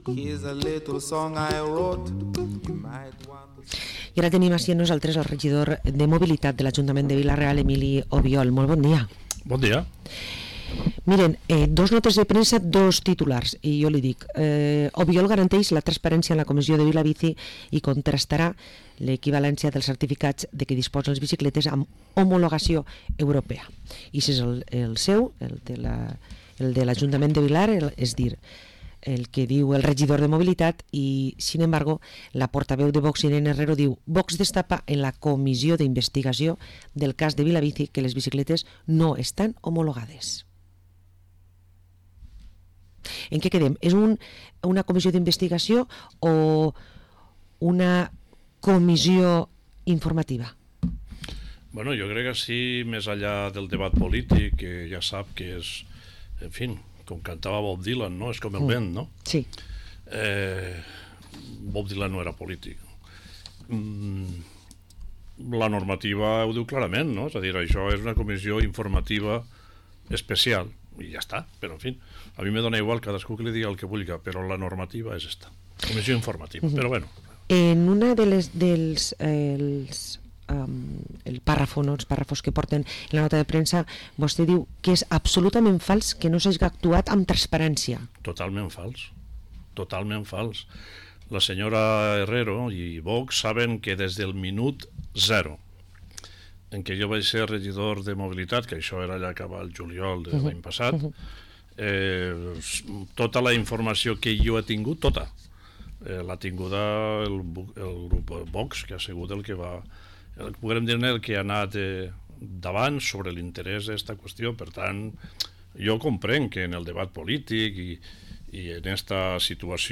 Parlem amb Emilio Obiol, regidor de mobilitat a l´Ajuntament de Vila-real